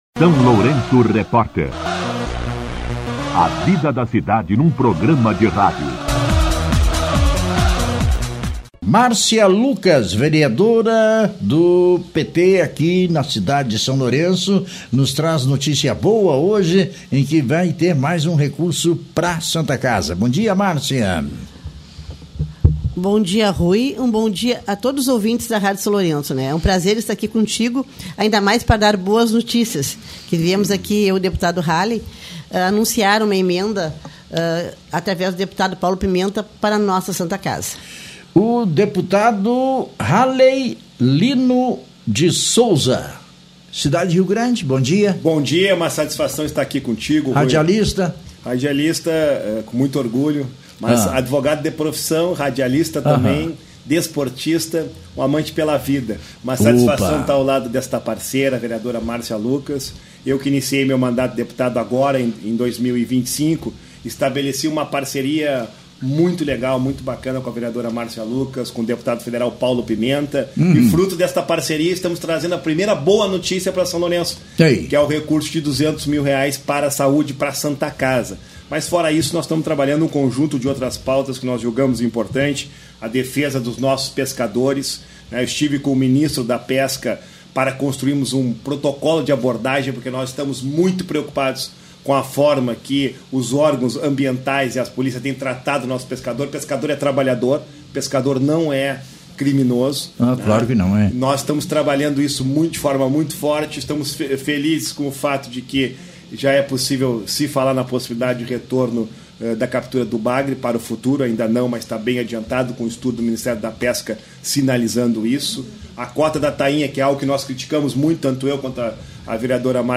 O deputado estadual Halley Lino de Souza (PT) e a vereadora Márcia Lucas (PT) participaram do SLR RÁDIO nesta quarta-feira (23), onde anunciaram a destinação de uma emenda parlamentar de custeio no valor de R$ 200 mil para a Santa Casa de Misericórdia de São Lourenço do Sul, viabilizada pelo deputado federal Paulo Pimenta (PT).